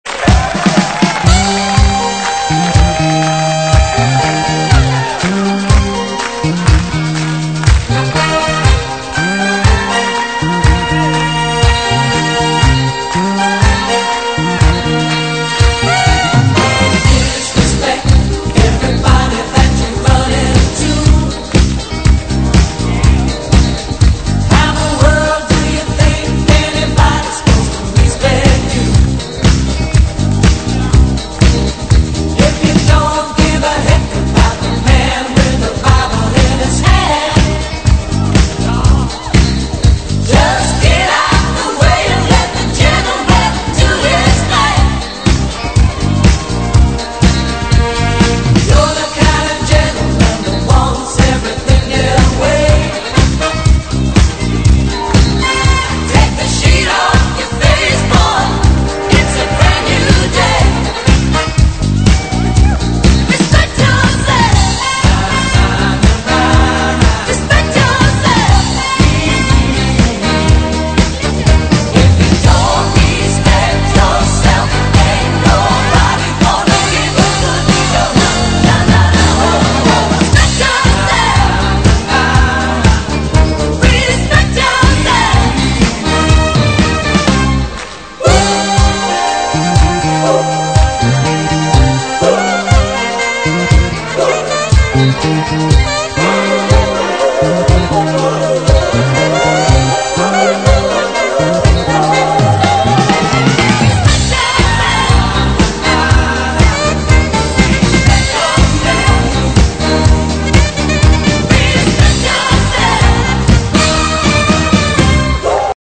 Category  Rock/Pop Albums, Easy Listening CDs
Studio/Live  Studio
Mono/Stereo  Stereo